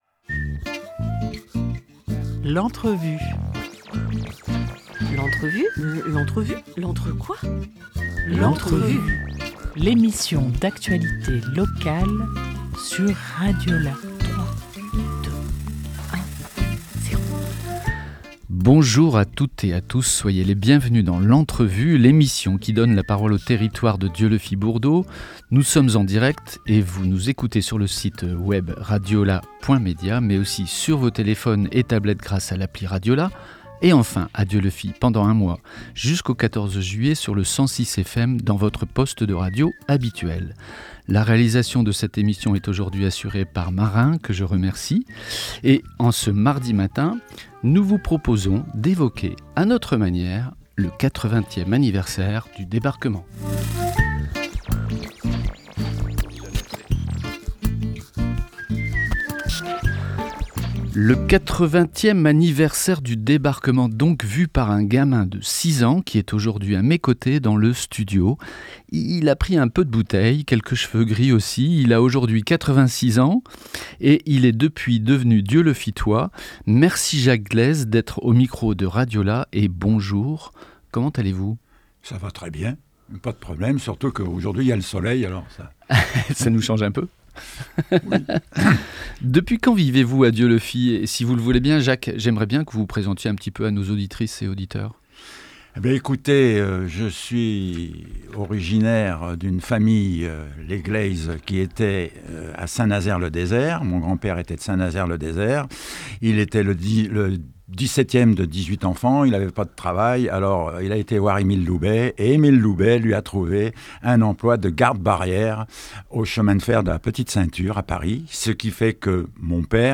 2 juillet 2024 11:15 | Dessine-moi une radio, Interview